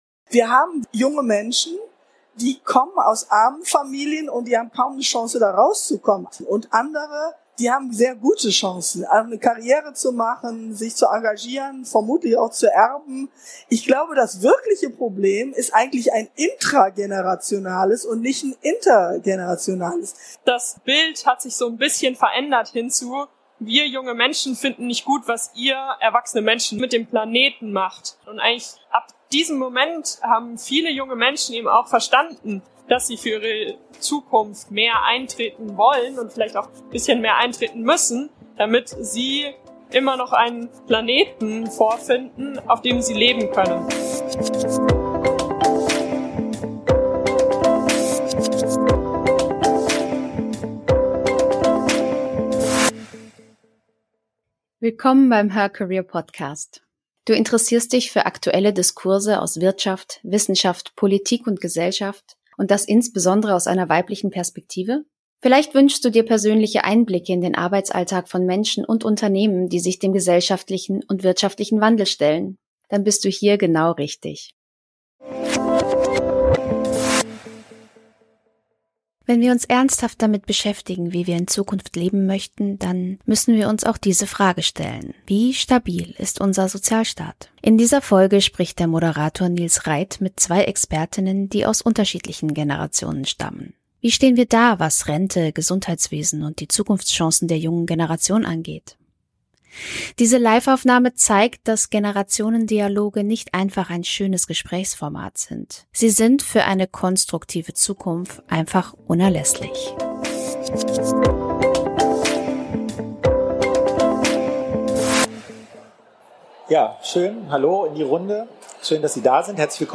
Wie stehen wir da, was Rente, Gesundheitswesen und die Zukunftschancen der jungen Generation angeht? In diesem Live-Gespräch diskutieren